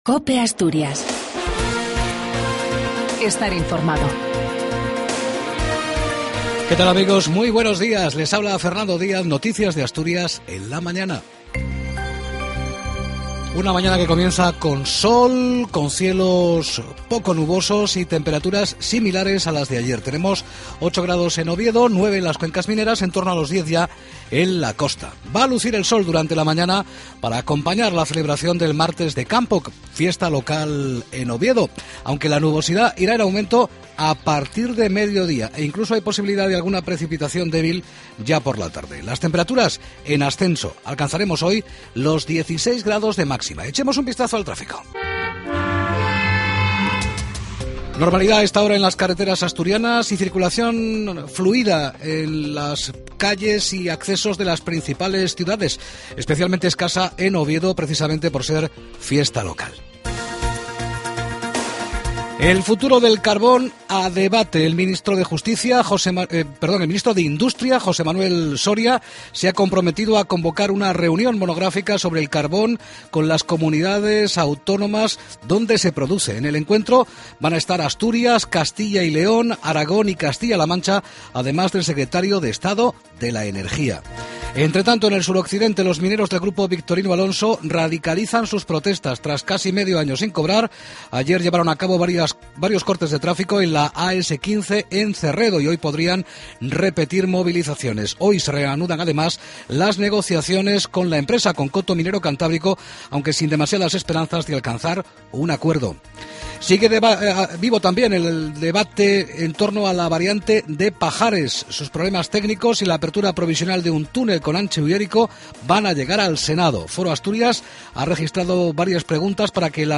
Redacción digital Madrid - Publicado el 21 may 2013, 09:43 - Actualizado 14 mar 2023, 12:00 1 min lectura Descargar Facebook Twitter Whatsapp Telegram Enviar por email Copiar enlace LAS NOTICIAS DE ASTURIAS A PRIMERA HORA DE LA MAÑANA.